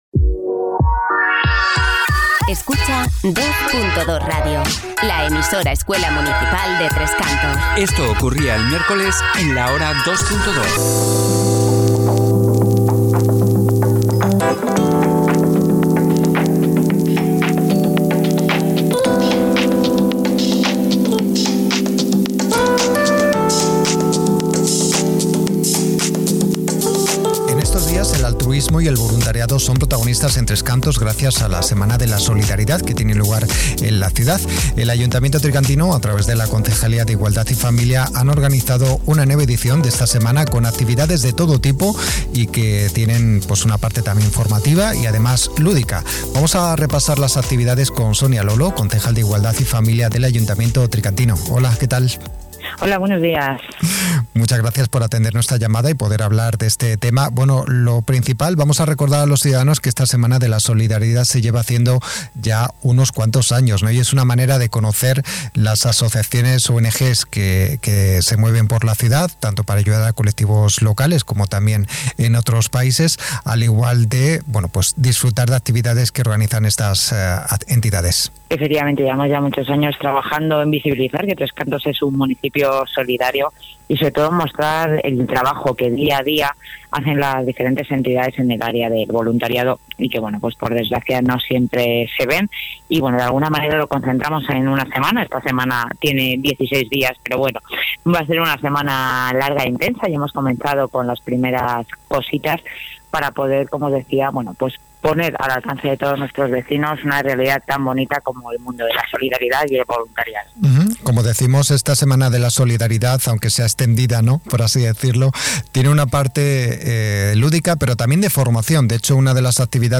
La Hora Dos Punto Dos : Entrevista Semana Solidaridad - 2.2 Radio
Conoceremos la programación de actividades de la Semana de la Solidaridad que tiene lugar en estos días en Tres Cantos con conciertos musicales, espectáculos y también la gala que premia a voluntarios.. Hablaremos con sonia lolo, concejal de Igualdad.
ENTREVISTA-SONIA-LOLO.mp3